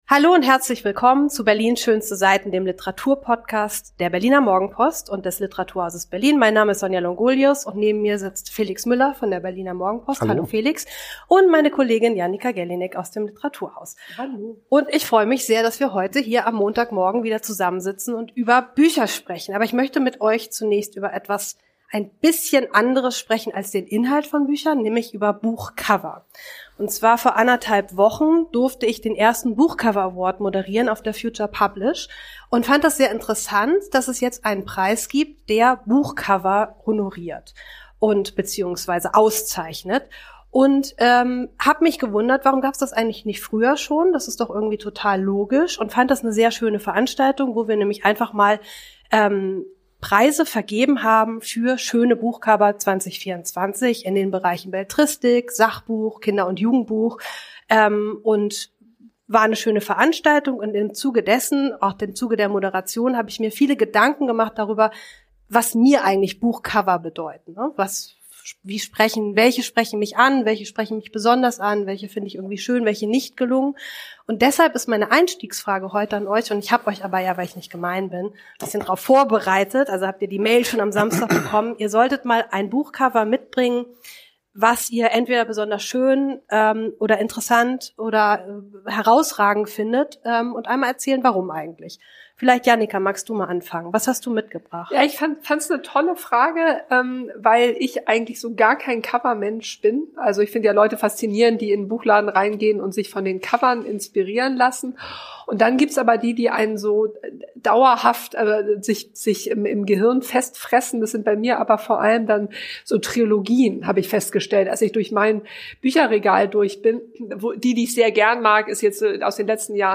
Drei Menschen, die sich für Literatur begeistern, treffen sich nun alle zwei Wochen, um sich darüber zu unterhalten, was sie derzeit im weiten Feld der Texte und Bücher bewegt.